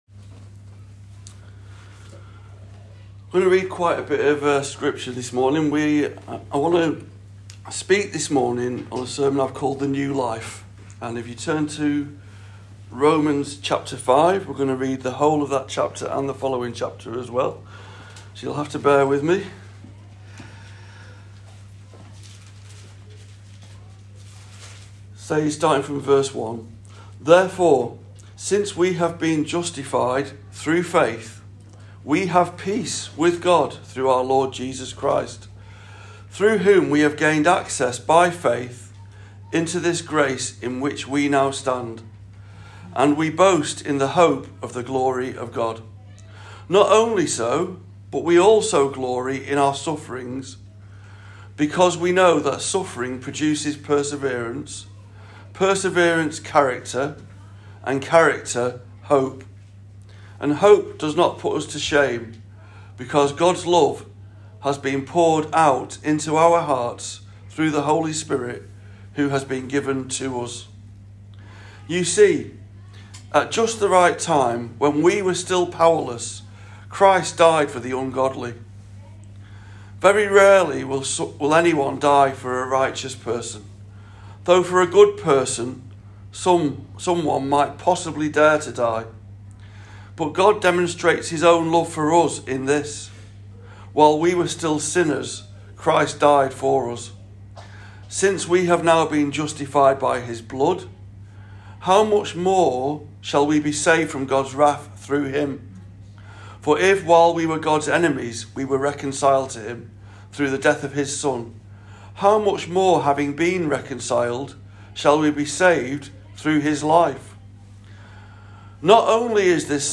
SERMON “THE NEW LIFE”